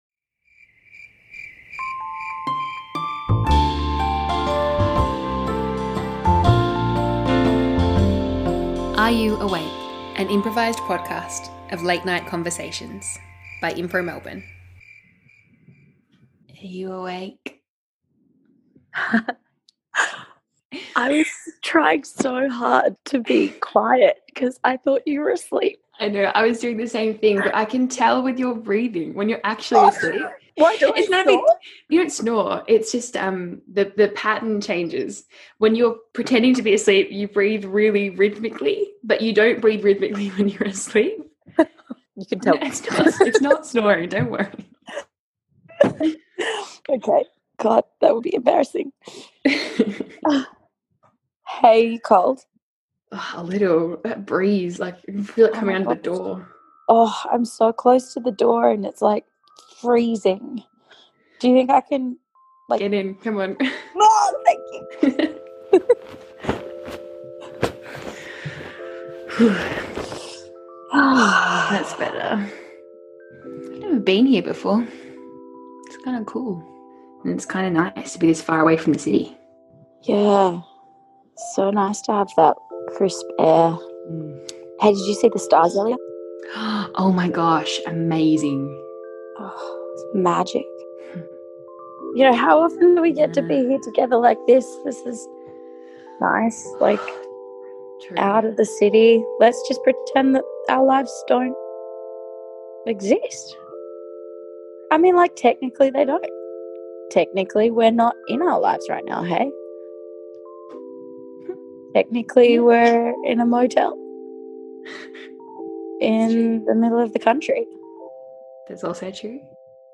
Join us on a late-night journey into the conversations that can only be heard in the middle of the night, when the lights are off and your guard is down. Each episode features two Impro Melbourne actors exploring those connections.
Are You Awake? an improvised podcast.